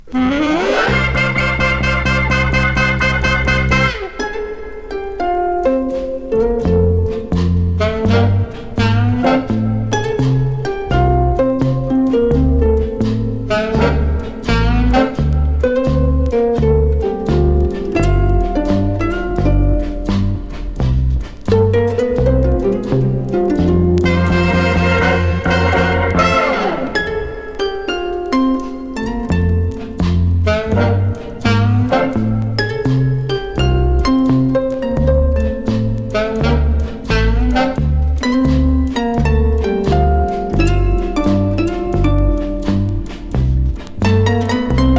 ハープの音ということもありますが、楽曲の中で響くきらめく演奏がたまりません。
Jazz, Pop, Easy Listening　USA　12inchレコード　33rpm　Stereo